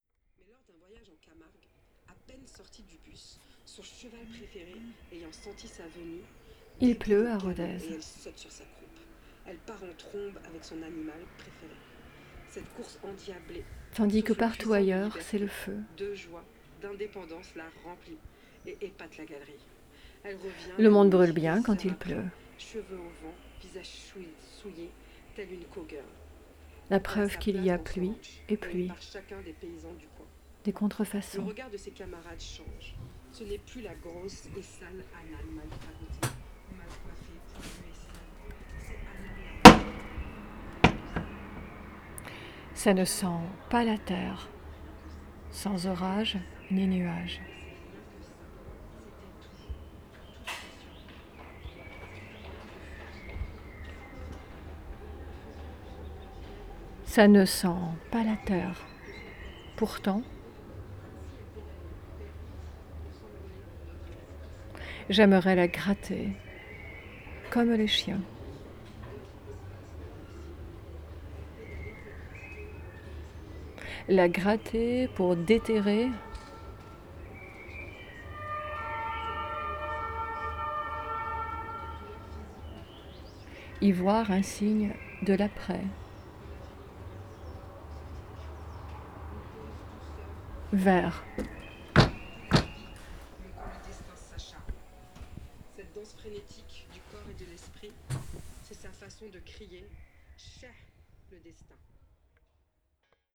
Les transpoèmes enregistrés sont des litanies.
Je reviens toujours à la fenêtre, à ces plans fixes aperçus : ma voix s’ouvre aux bruits du dehors, tandis que dans Voyage autour de ma chambre, la fenêtre était proscrite du récit car elle ouvrait sur le lointain.
La profondeur du champ sonore que l’on perçoit construit un pont entre le texte et le contexte de crise qui apparaît par le prisme de bruits : j’écoute la radio, puis me lève, ouvre la fenêtre et écoute le dehors, reviens vers mon ordinateur où une autre émission radio a commencé ; une autre fois, je me lève de mon bureau pour percevoir au dehors les applaudissements adressés au personnel hospitalier à 20 heures, qui ponctuent la semaine.